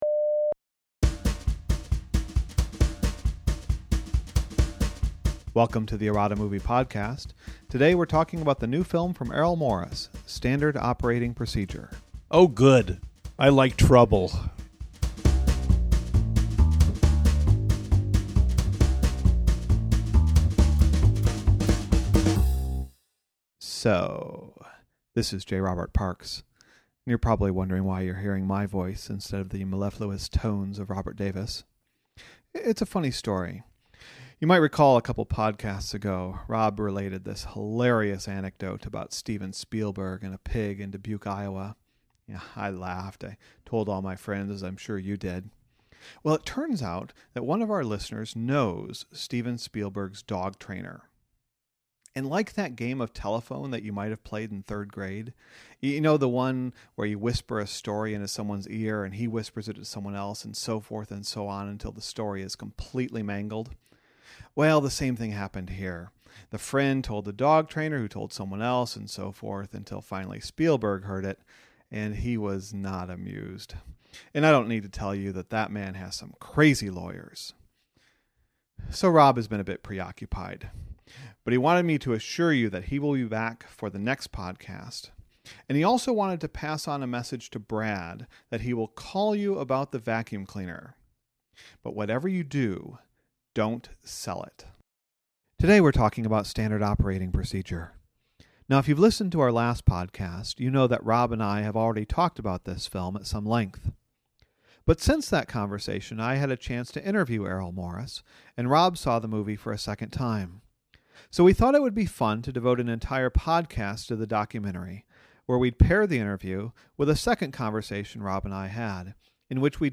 Errata: Interview and Discussion: Errol Morris